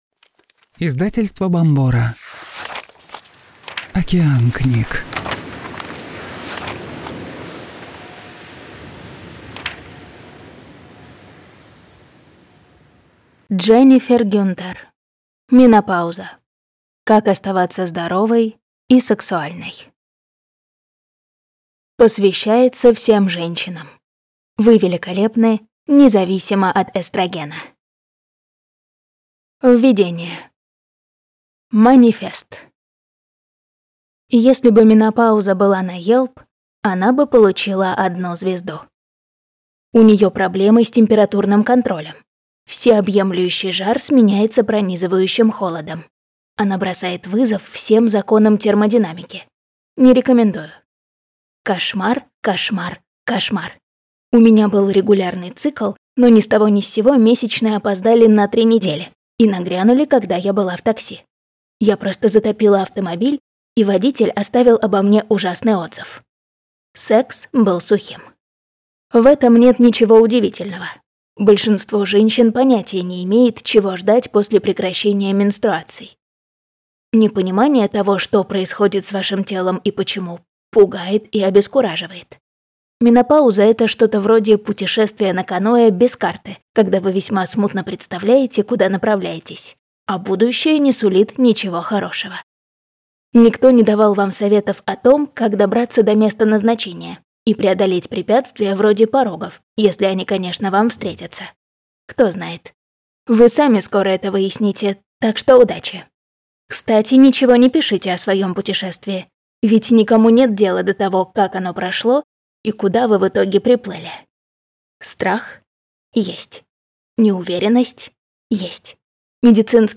Аудиокнига Климакс без страха. Как пережить этот период, облегчить его симптомы и обрести гармонию | Библиотека аудиокниг